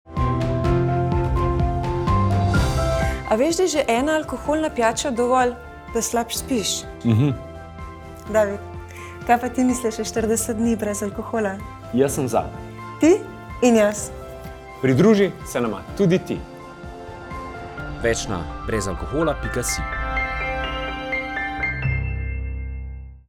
Video : Tiskani oglas: Banner : * TV oglas * prenos TV oglas * oglas 83×120 * oglas 97×90 * oglas 108×80 * oglas 173×118 * banner 336×280 * banner 300×300 * banner 300×250 Radijski oglas Plakat ( PDF )